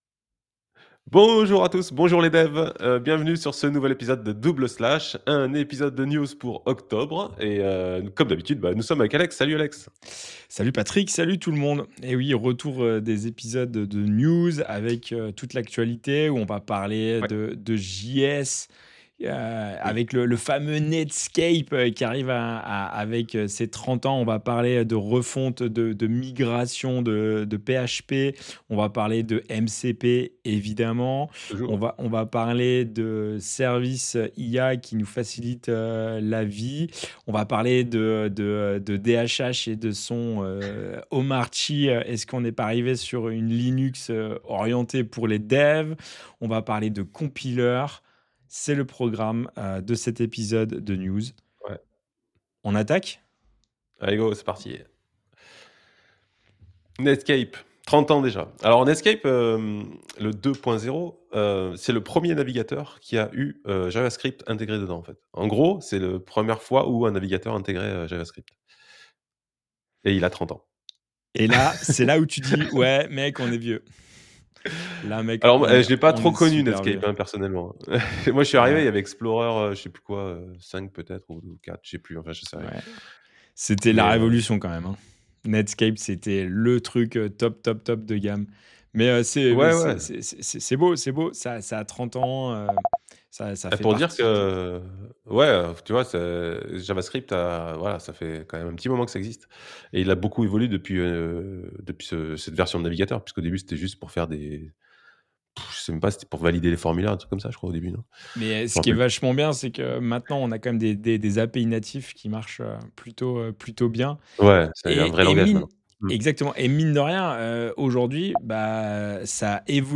Double Slash, un podcast avec 2 animateurs qui se retrouvent pour discuter des outils et des techniques pour le développement moderne de site web et d’application web. Retrouvez-nous régulièrement pour parler de sujets variés tels que la JAMStack, l’accessibilité, l’écoconception, React JS, Vue JS et des retours d’expériences sur des implémentations.